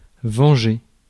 Ääntäminen
IPA: /vɑ̃.ʒe/